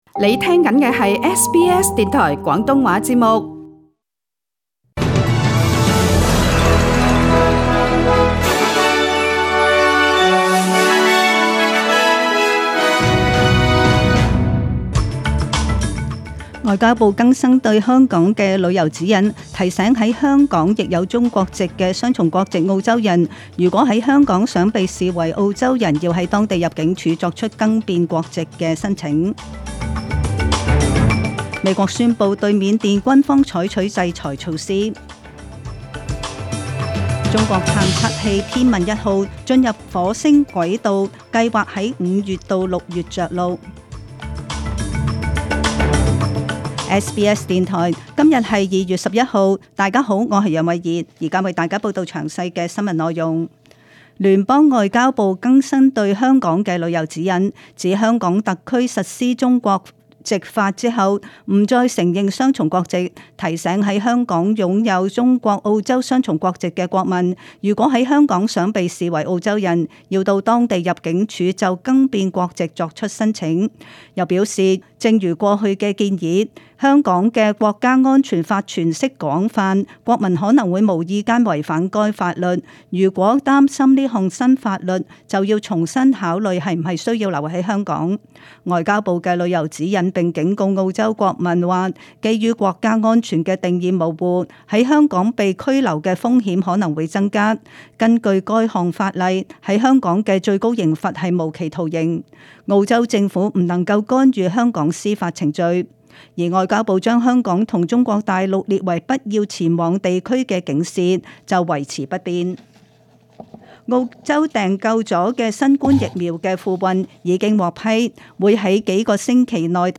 SBS中文新聞 （二月十一日）